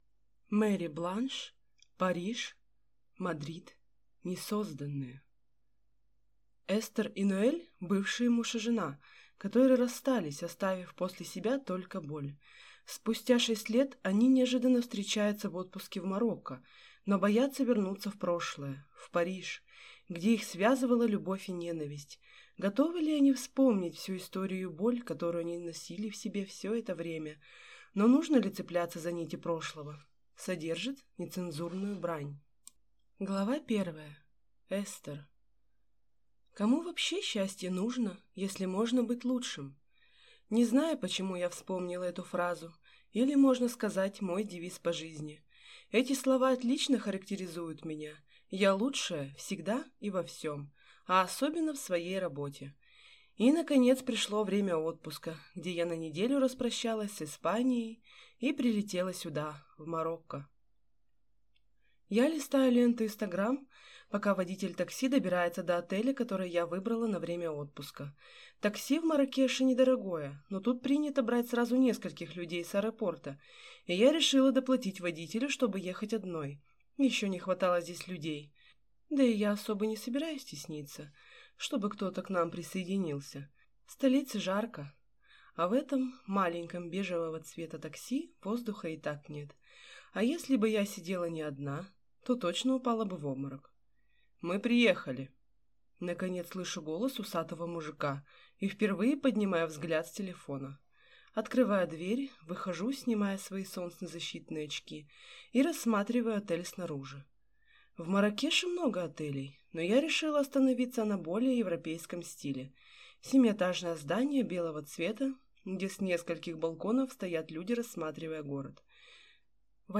Аудиокнига Париж. Мадрид. Несозданные | Библиотека аудиокниг